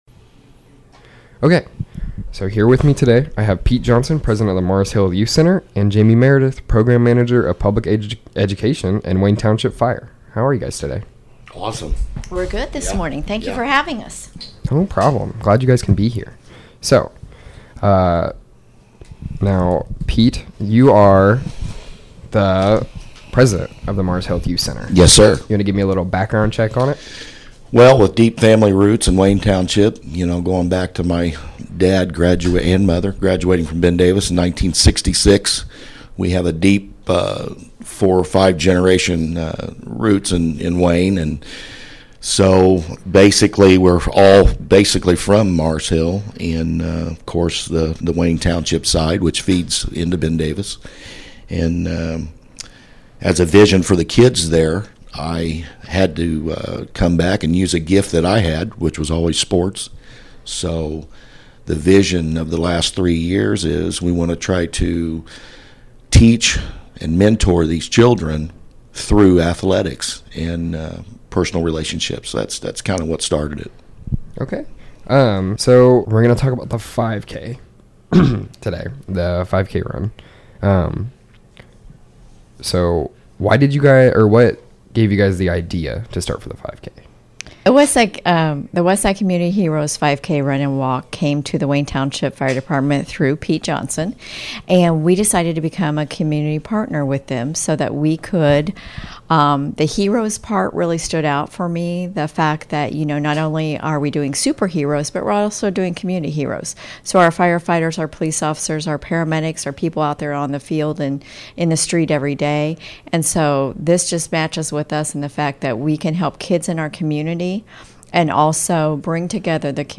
Westside Community Hero's 5K Interview